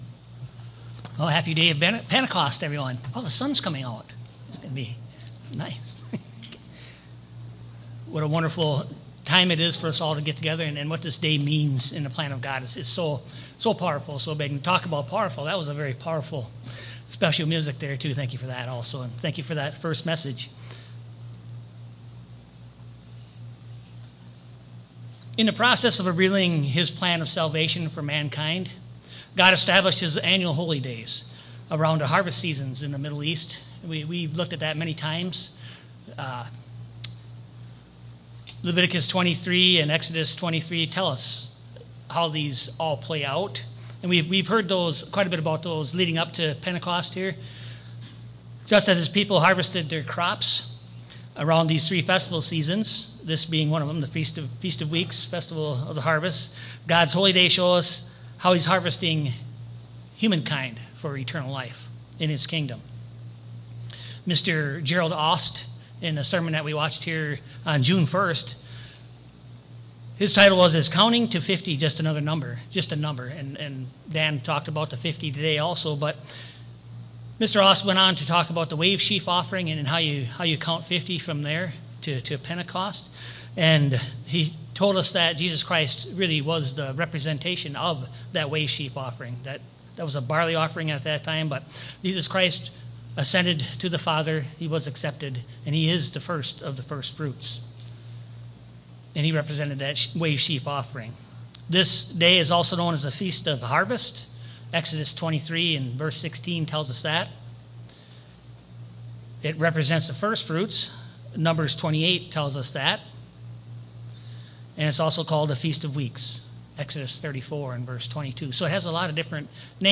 In this message on Pentecost, it emphasized the profound significance of this day in God's plan of salvation, highlighting that God's annual holy days, established around the Middle Eastern harvest seasons, symbolize His harvesting of humankind for eternal life.